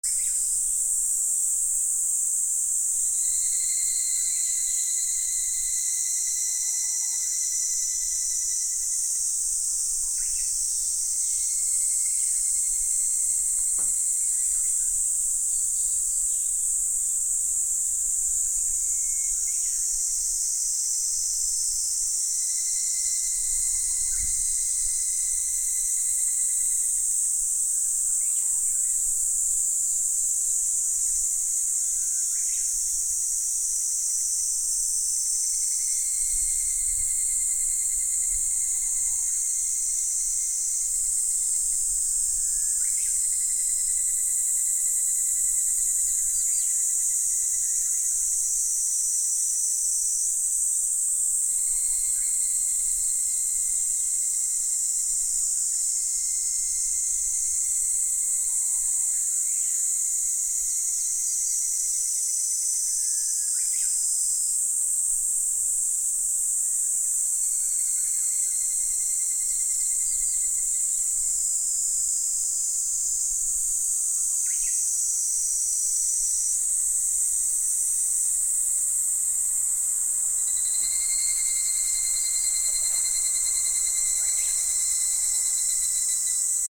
/ B｜環境音(自然) / B-25 ｜セミの鳴き声 / セミの鳴き声_60_山(森林)
山 セミの鳴き声 4